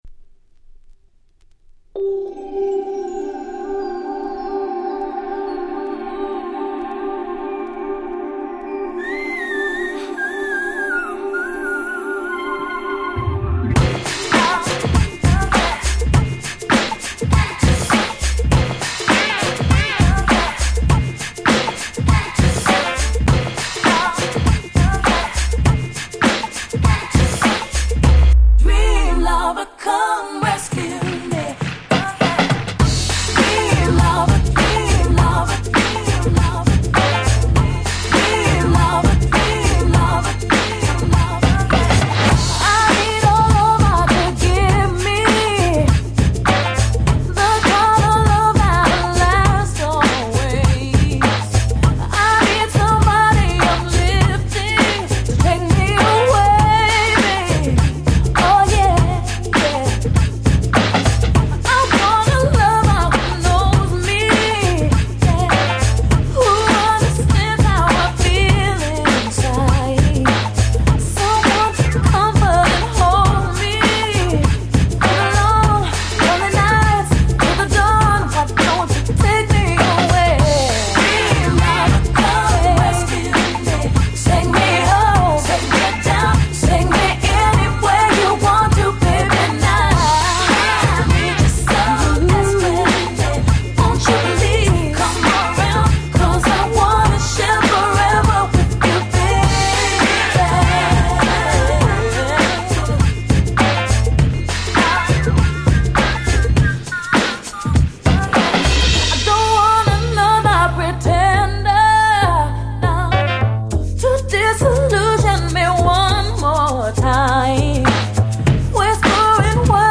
93' Super Hit R&B !!
跳ねたBeatでClub仕様の